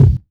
• Low Tone Bass Drum Single Shot G Key 115.wav
Royality free kick drum single shot tuned to the G note. Loudest frequency: 135Hz
low-tone-bass-drum-single-shot-g-key-115-oyz.wav